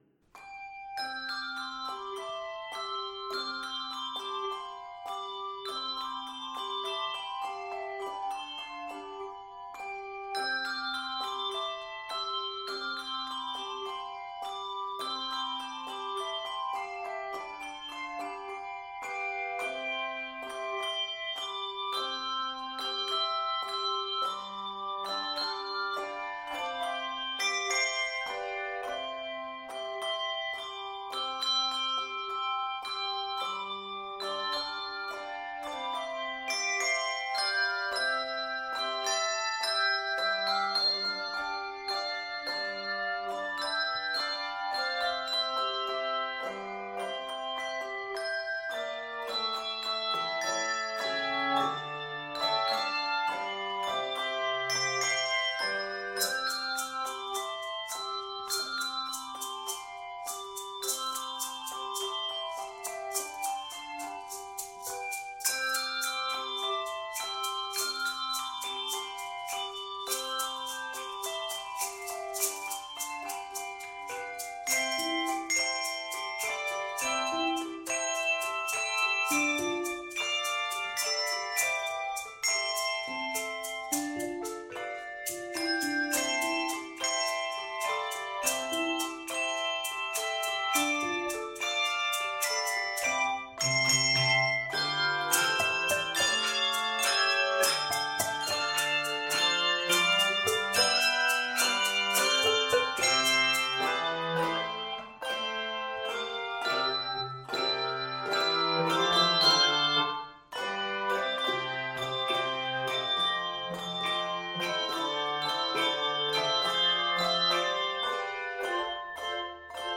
jaunty arrangement
Key of C Major.